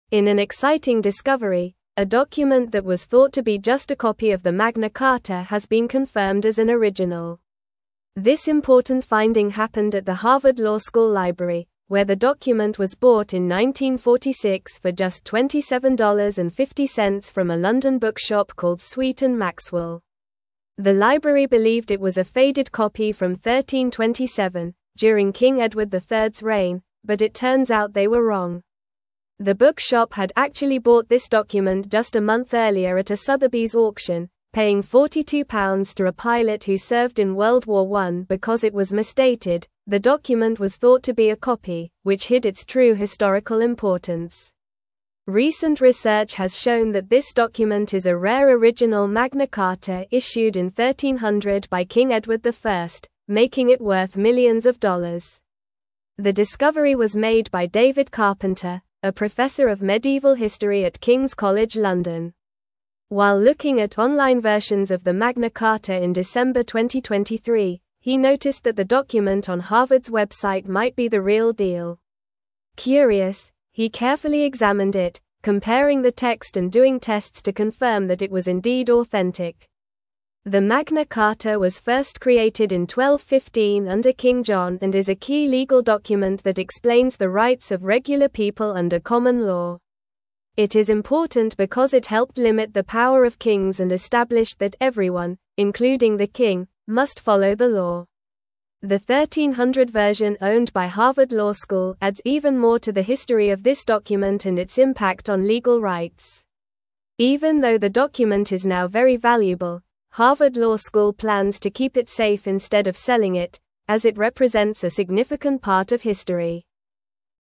Normal
English-Newsroom-Upper-Intermediate-NORMAL-Reading-Harvard-Library-Confirms-Original-Magna-Carta-Discovery.mp3